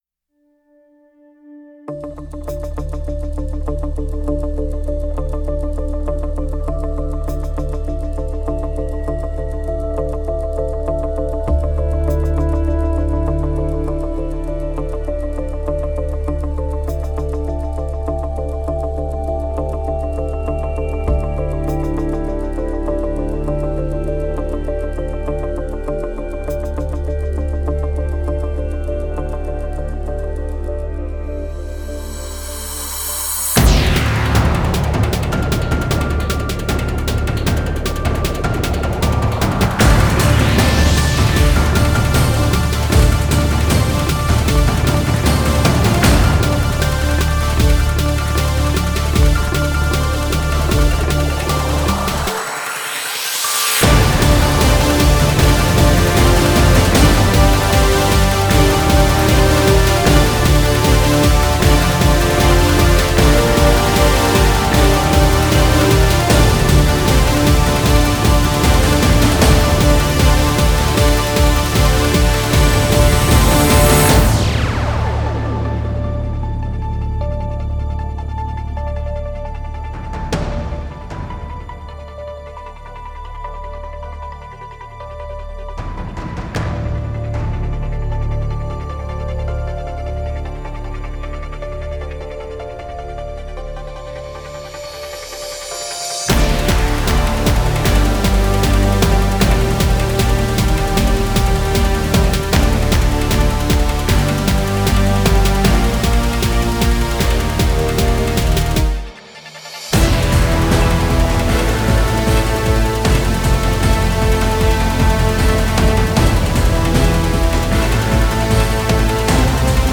Genre: Score.